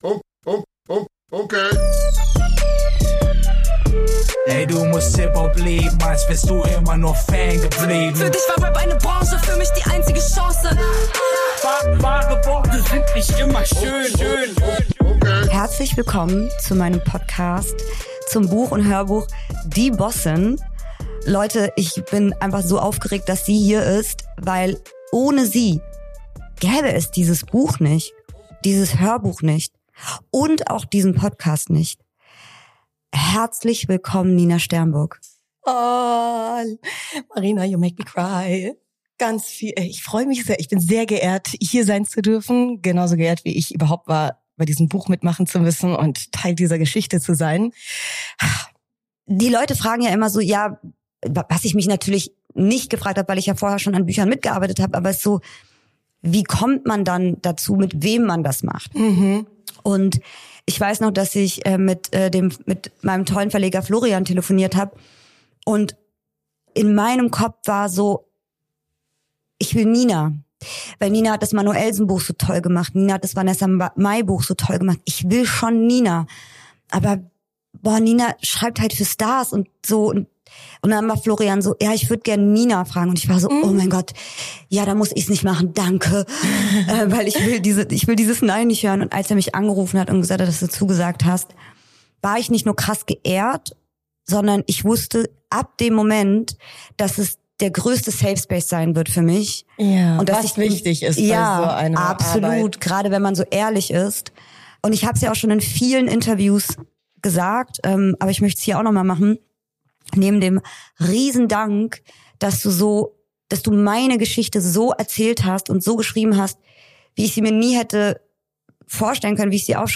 Hier führt die Autorin vertiefende Gespräche zum Hörbuch mit wichtigen Menschen aus ihrem Berufs- und Privatleben.